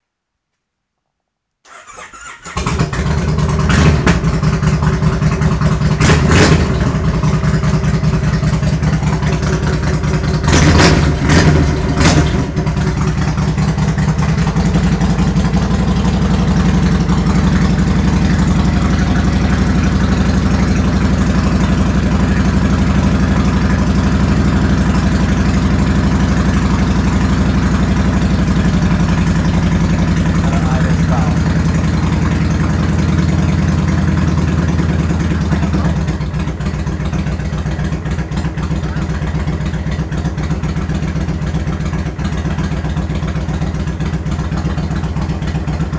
V&H_1st_start.wav